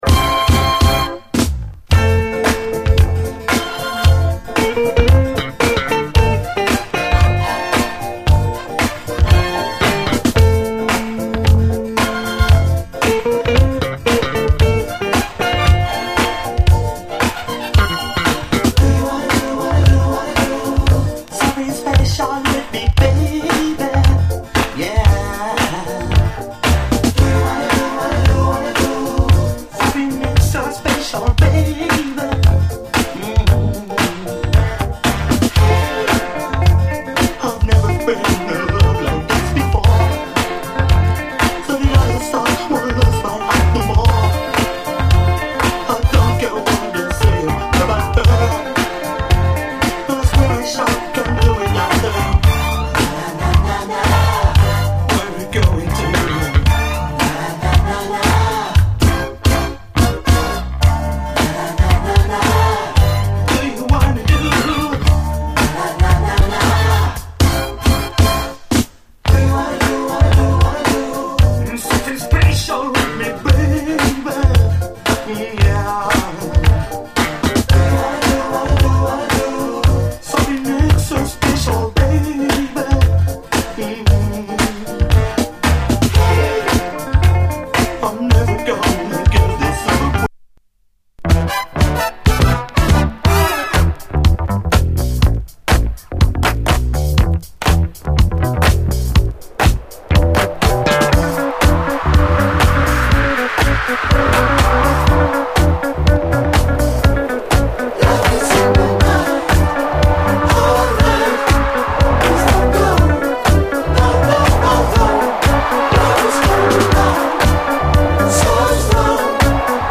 スペイシーなエレピの揺らめき、浮遊感が絶品のグレイト・ジャズ・ファンク〜クロスオーヴァー揃い！
抜群のコズミック度と濃厚なグルーヴの最高メロウ・コズミック・ソウル
爽やかにシンセがたなびく、美麗ジャジー・ソウル